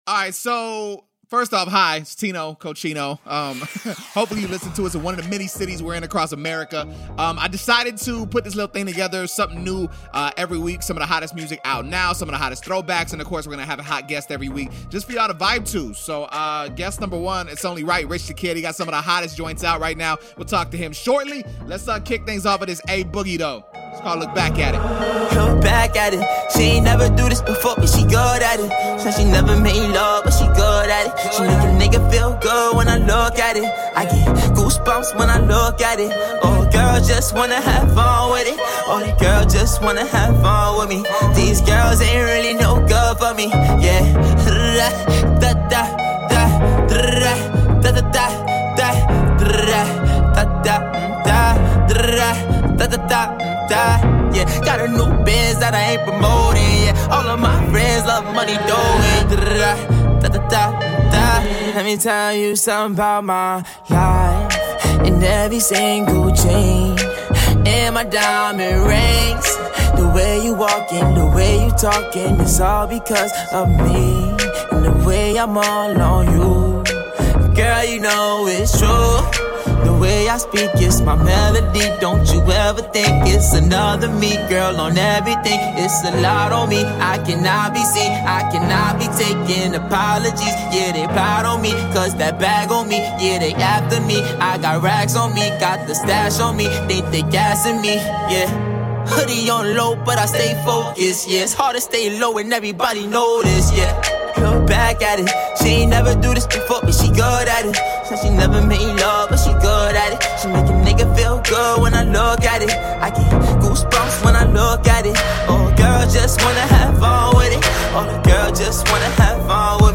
I sat down with a studio audience to talk with him about his mothers initial support, smoking with Snoop, and so much more!